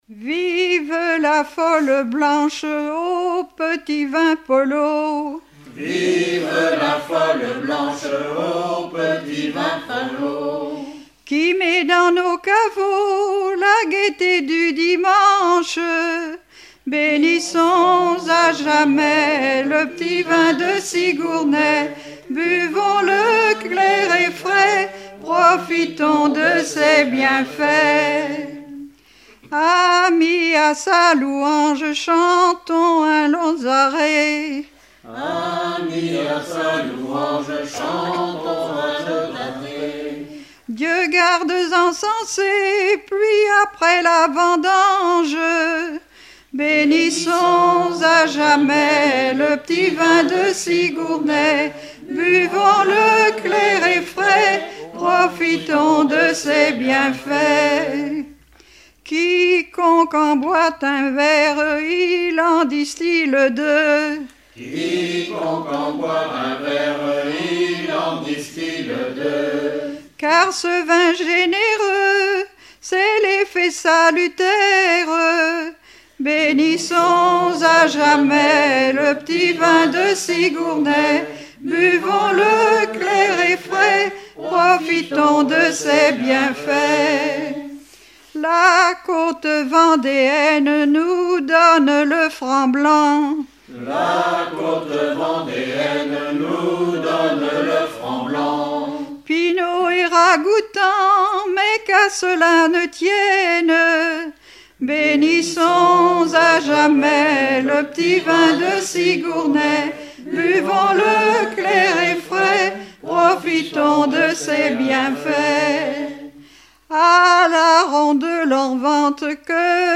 cantique traditionnel montfortain
circonstance : bachique
Pièce musicale éditée